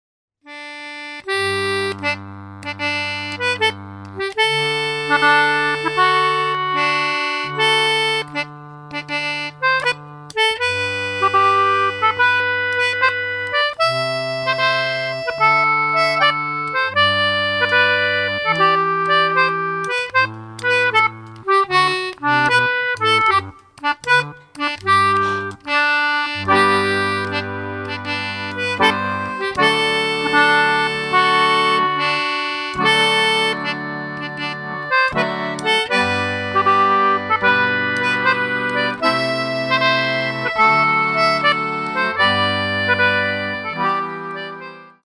treble & baritone English concertinas
anglo and duet concertinas.